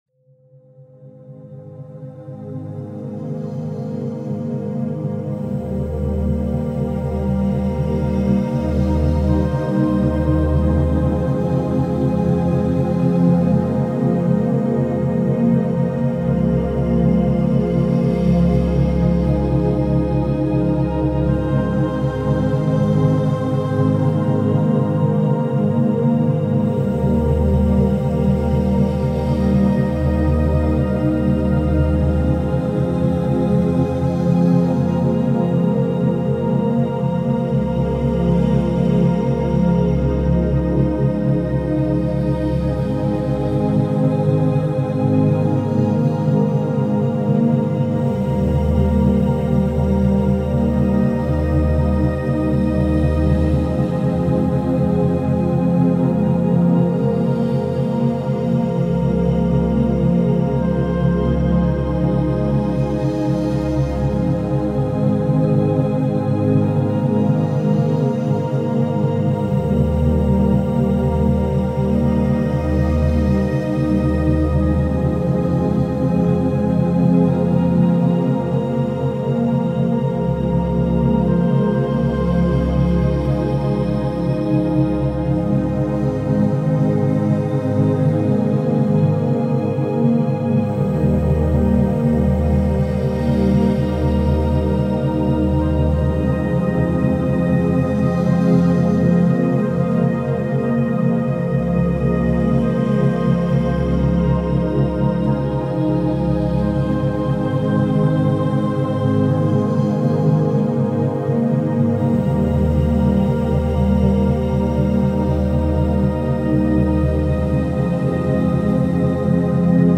Quantum Focus – Boost Memory and Concentration with Isochronic Tones
Searching for the perfect background sound to elevate your concentration and motivation?